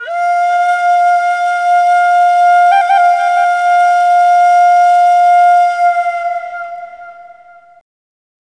EASTFLUTE4-L.wav